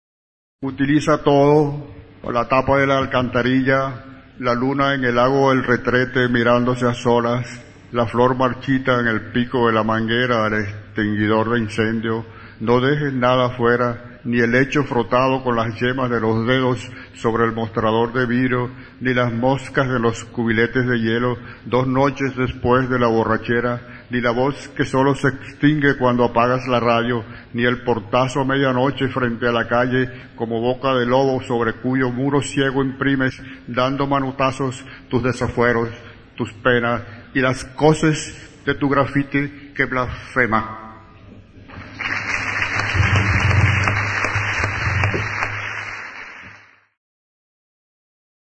El audio pertenece las "Memorias del IX Festival Internacional de Poesía de Medellín", disco compacto editado por la revista Prometeo, organizadora de dicho Festival, actualmente el de mayor concurrencia a nivel mundial. La grabación en vivo contiene las voces de los 25 participantes al IX Festival, entre las cuales pueden oirse lecturas en guaraní, punjabi, inglés, sueco, árabe, francés, japonés, tuareg y alemán, con sus respectivas traducciones a nuestro idioma.